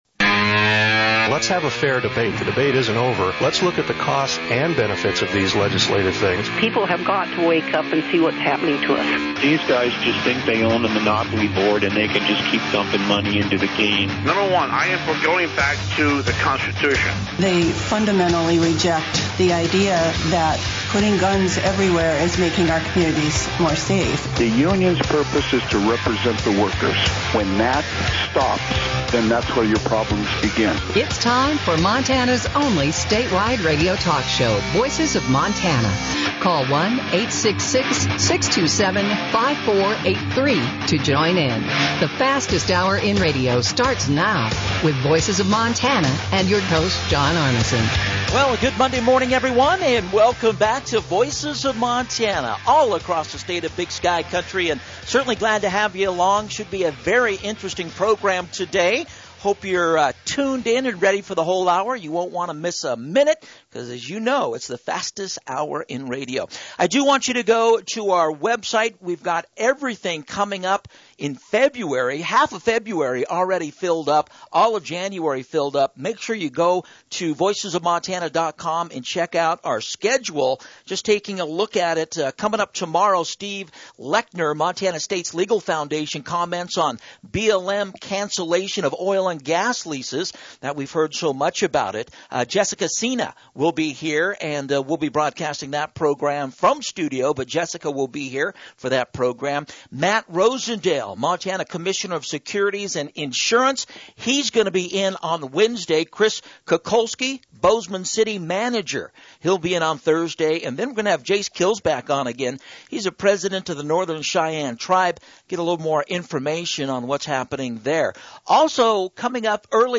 Voices of Montana Radio Show